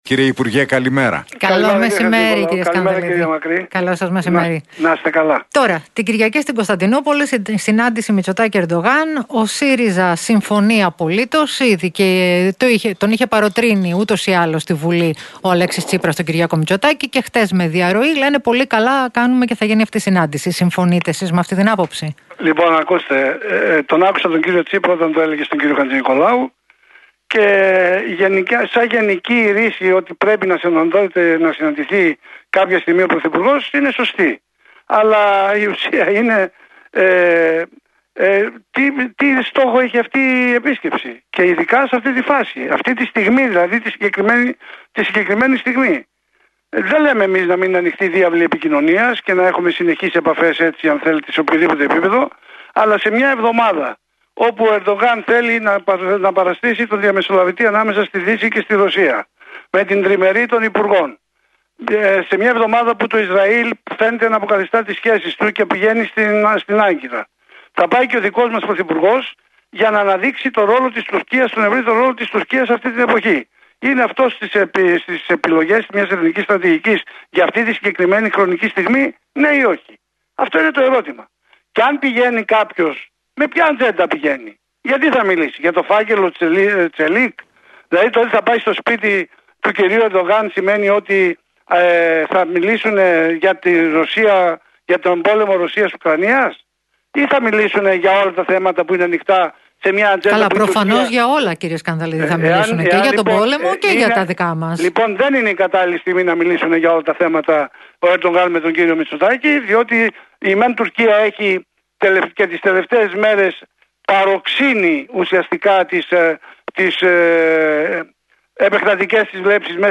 Σκανδαλίδης στον Realfm 97,8: Δεν είναι η κατάλληλη στιγμή να μιλήσουν για όλα τα θέματα ο κ. Μητσοτάκης με τον κ. Ερντογάν